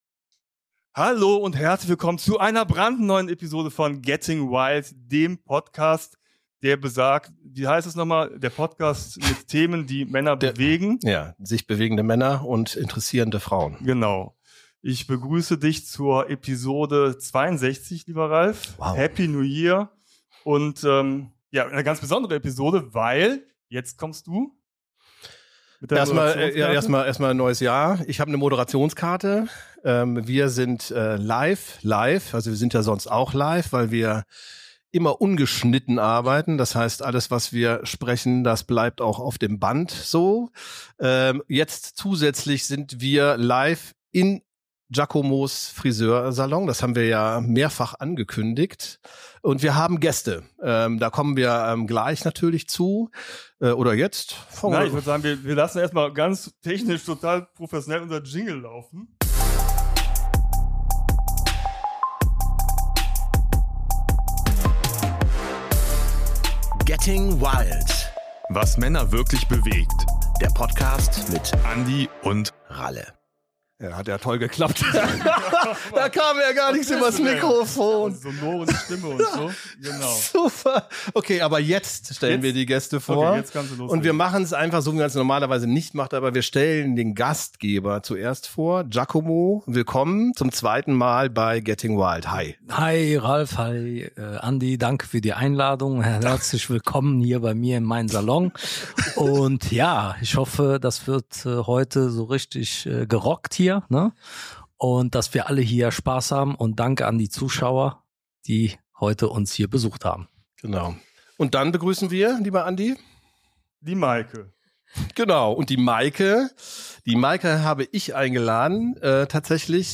Family & Friends, dazu eine Location, die zum unbeschwerten Talkaufgalopp besser nicht sein könnte: ein Friseursalon.